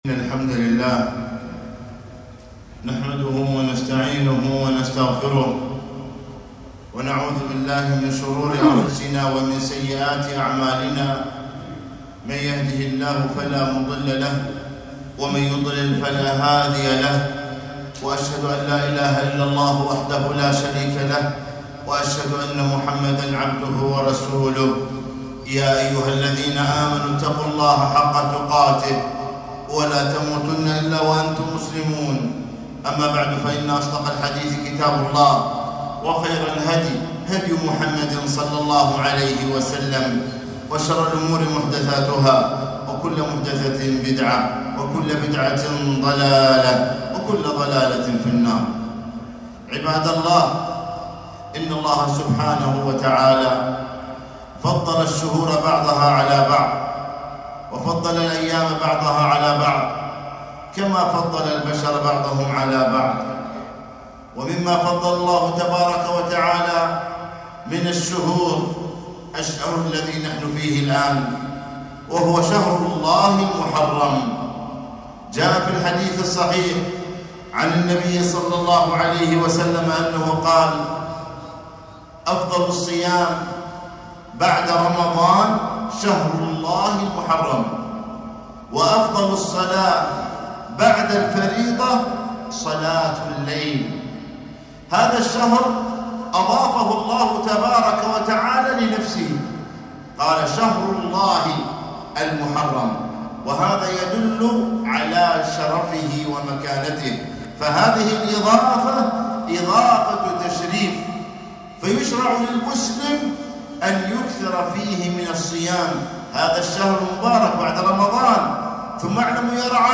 خطبة - شهر الله المحرم